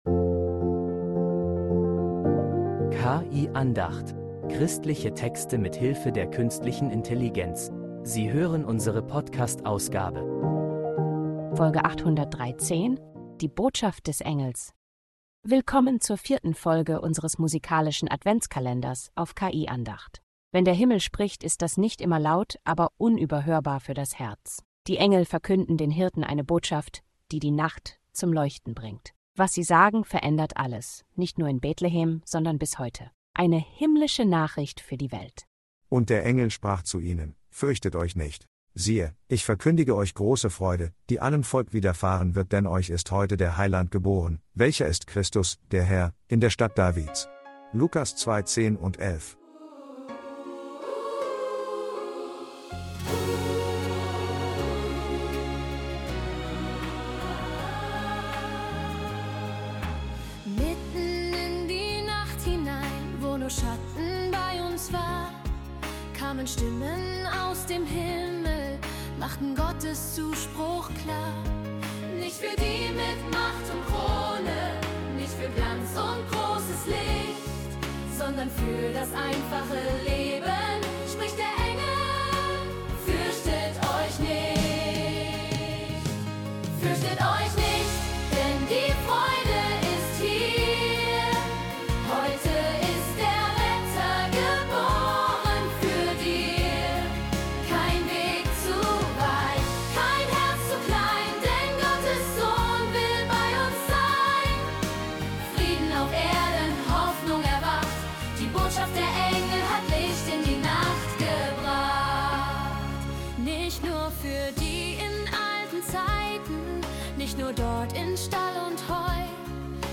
sie dir mit einem schwungvollen Popsong ganz neu zu Gehör.